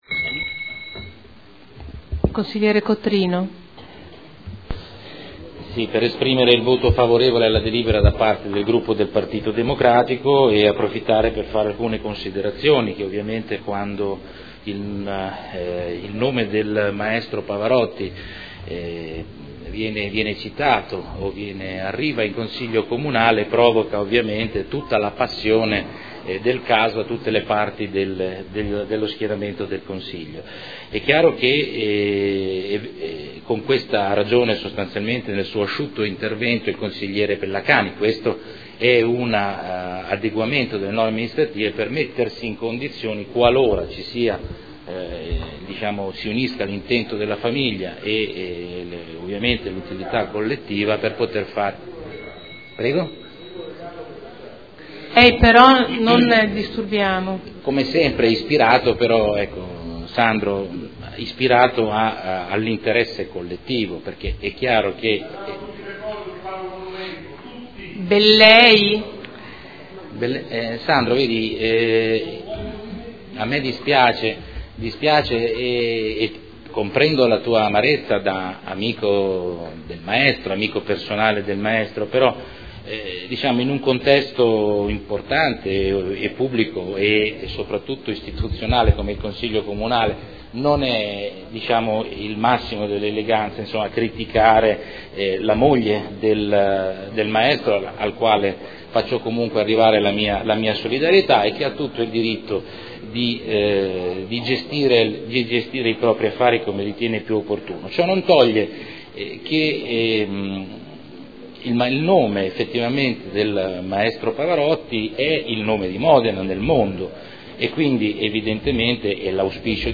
Salvatore Cotrino — Sito Audio Consiglio Comunale
Dichiarazioni di voto